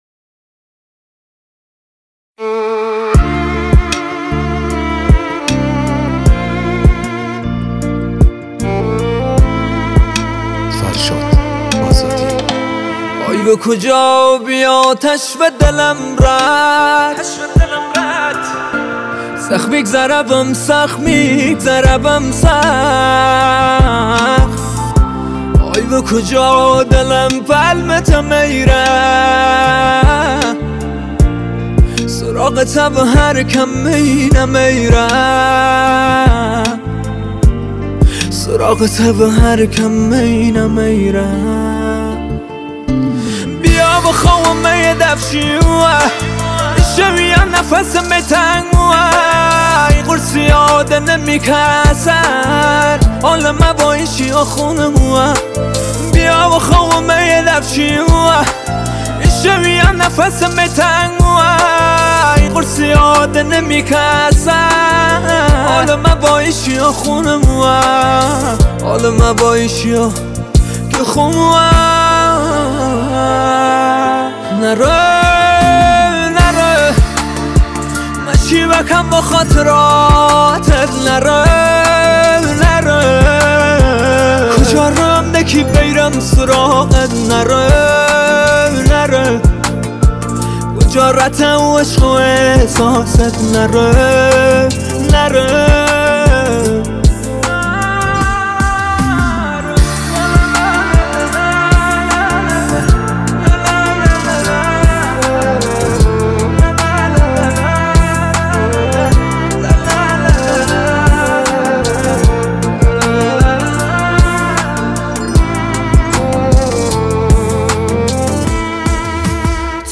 آهنگ لری و لکی